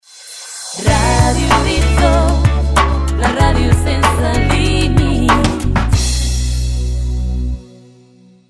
Sintonia genèrica lenta de la ràdio amb identificació